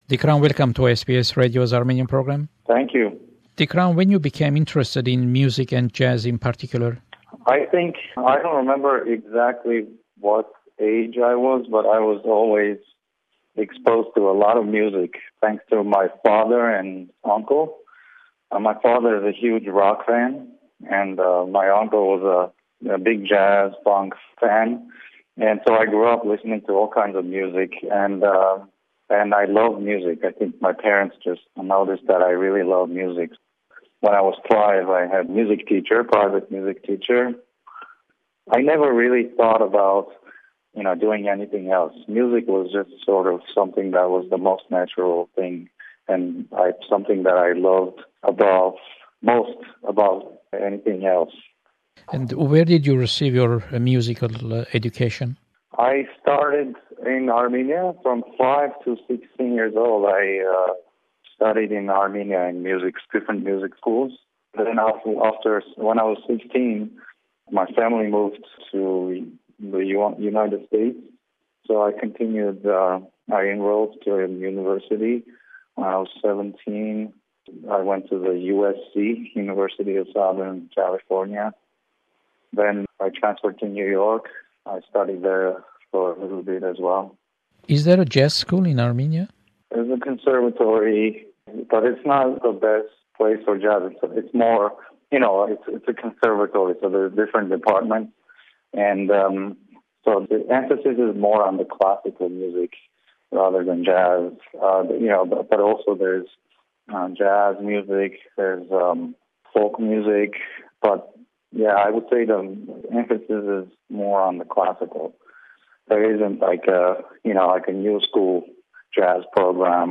Interview with jazz pianist Tigran Hamasyan from Yerevan. Tigran will perform in Brisbane, Sydney and Melbourne.